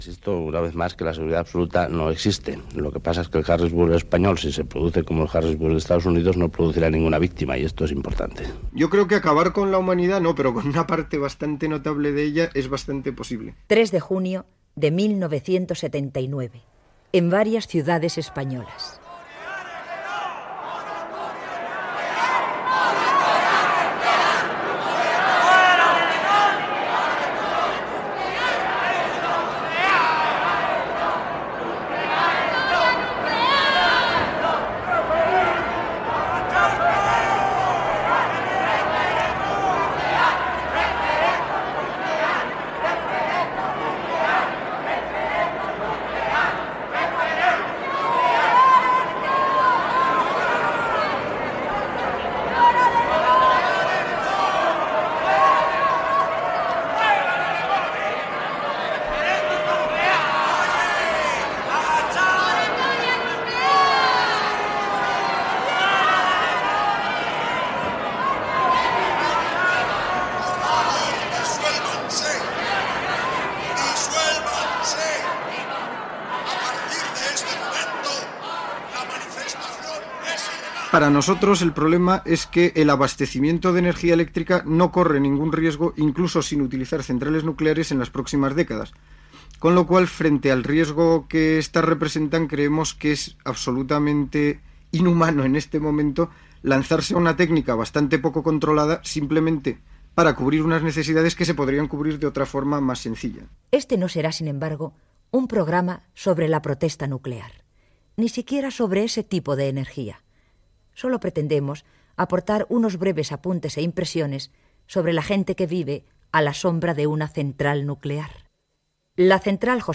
"La central", un reportatge sobre el perill de viure a prop d'una central nuclear
Informatiu